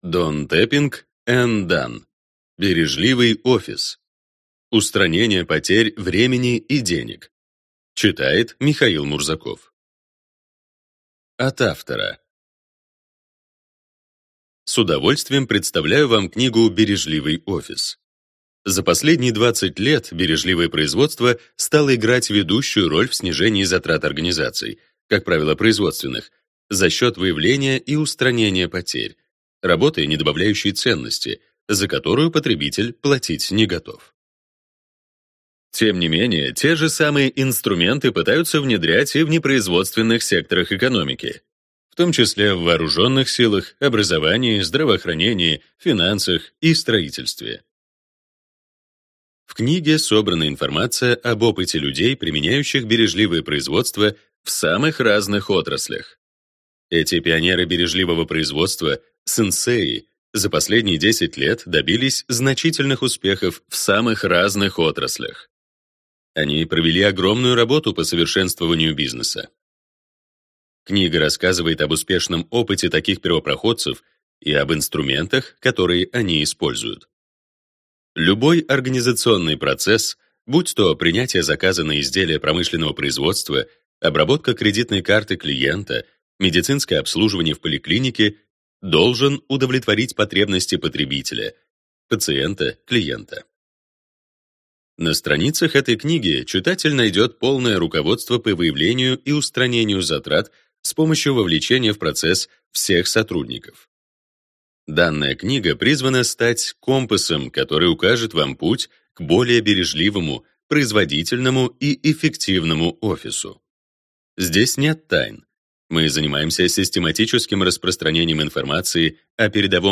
Аудиокнига Бережливый офис: Устранение потерь времени и денег | Библиотека аудиокниг